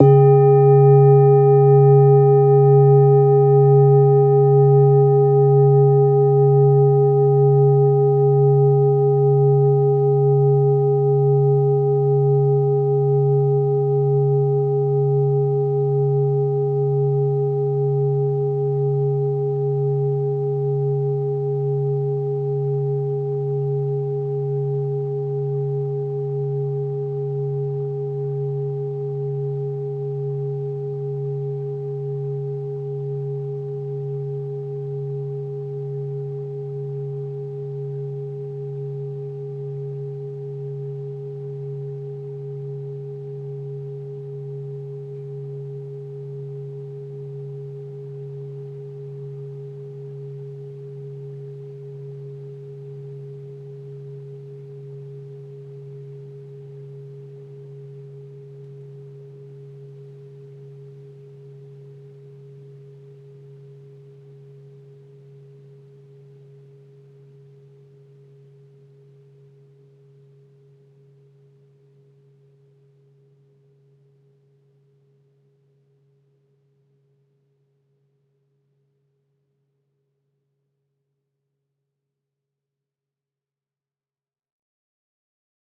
jan-bowl4-soft-C#2-mf.wav